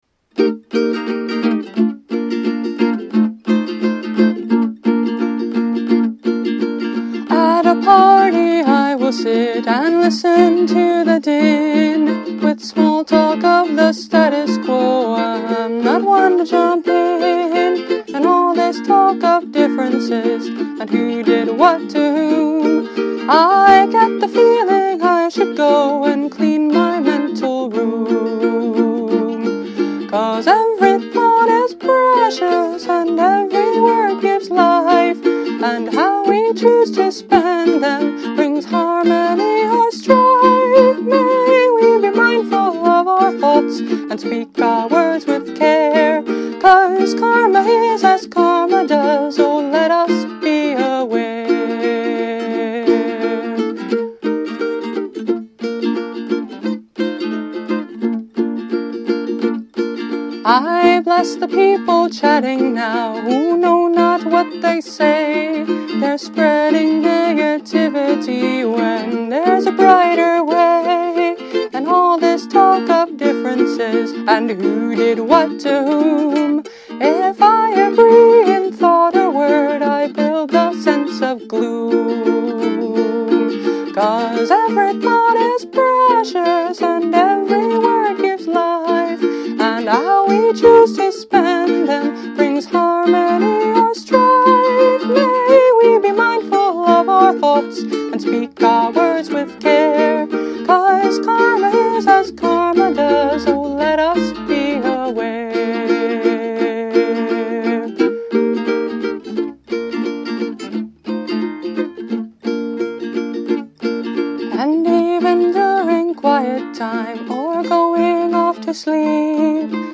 Instrument: Eventide – Mahogany Concert Ukulele